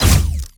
GUNAuto_Plasmid Machinegun C Single_03_SFRMS_SCIWPNS.wav